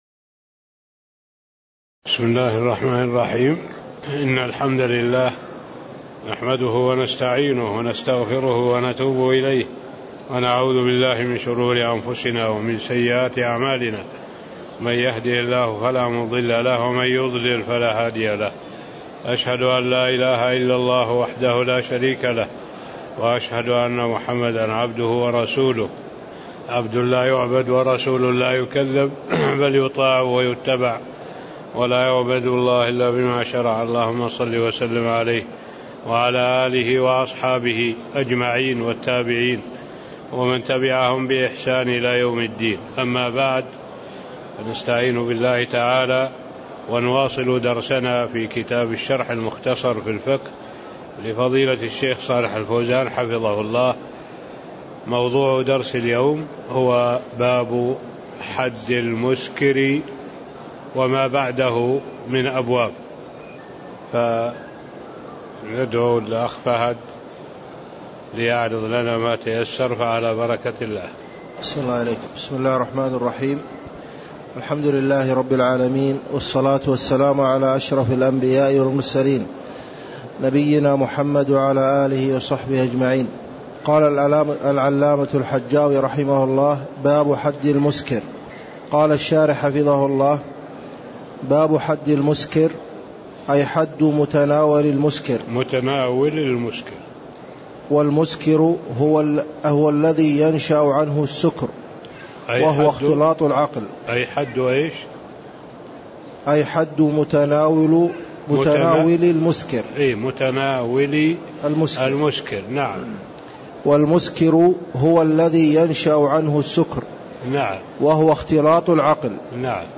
تاريخ النشر ٢٢ شوال ١٤٣٥ هـ المكان: المسجد النبوي الشيخ